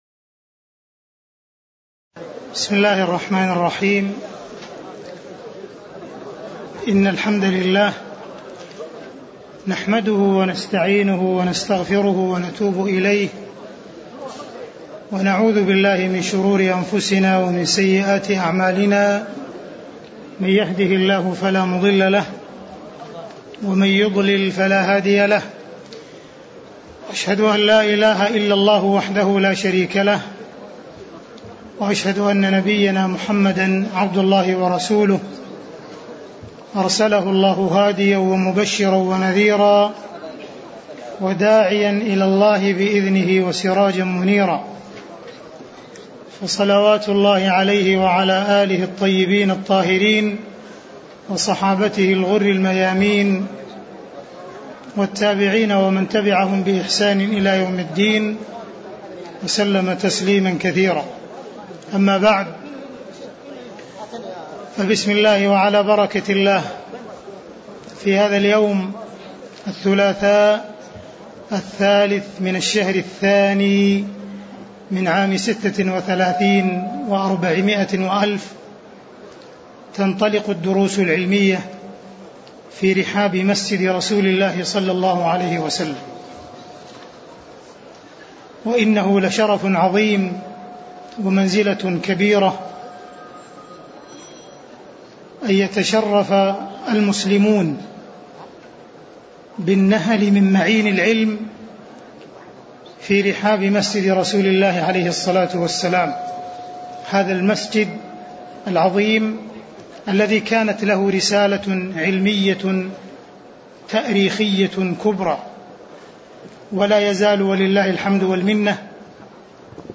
تاريخ النشر ٢ ربيع الأول ١٤٣٦ المكان: المسجد النبوي الشيخ: معالي الشيخ أ.د عبدالرحمن بن عبدالعزيز السديس معالي الشيخ أ.د عبدالرحمن بن عبدالعزيز السديس 01 المقدمة